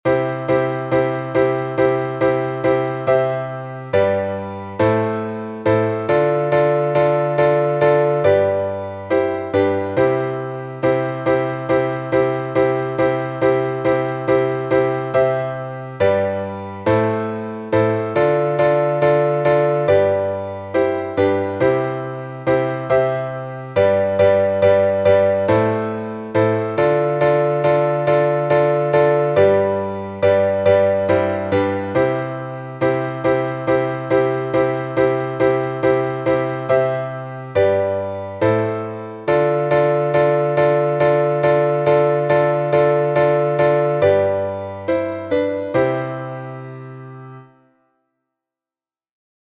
Малый знаменный распев, глас 4